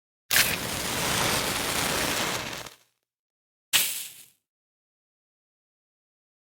Match Flame and Extinguish
UI/UX
yt_R1bXRRuEdH8_match_flame_and_extinguish.mp3